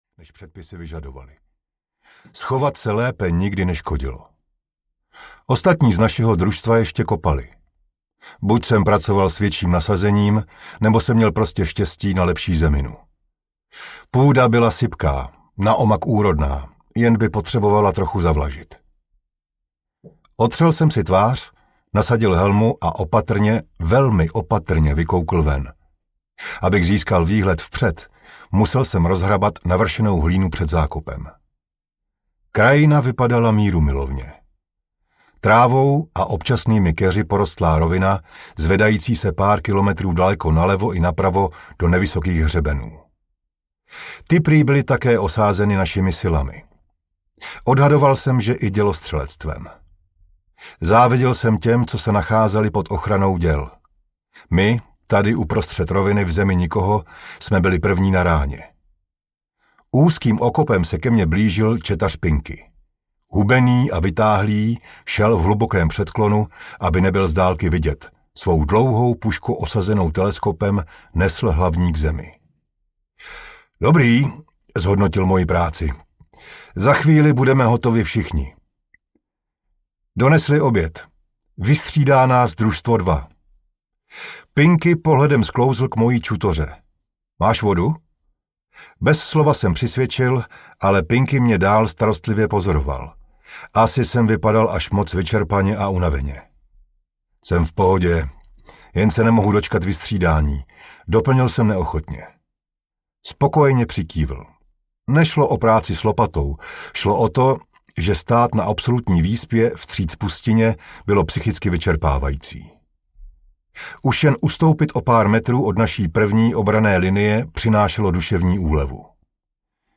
Veterán audiokniha
Ukázka z knihy